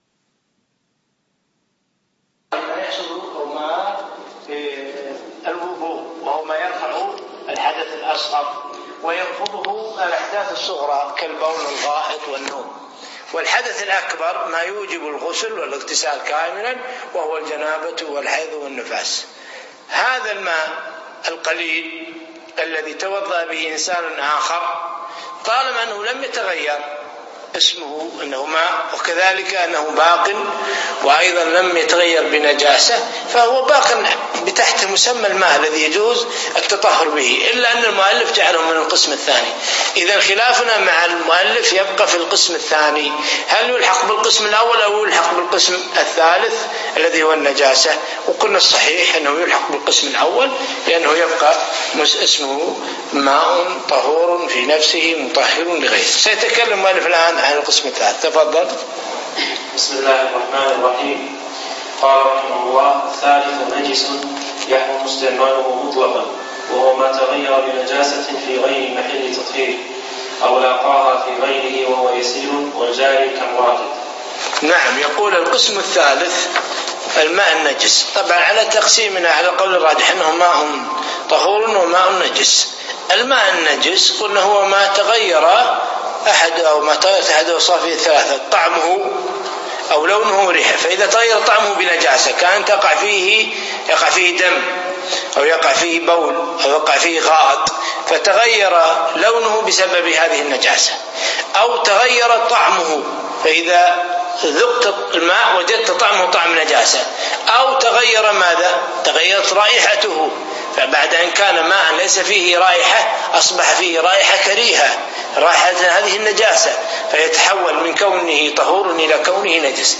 يوم الأحد 5 4 2015 في مسجد عبدالله النوري منطقة جابر الأحمد
الدرس الثاني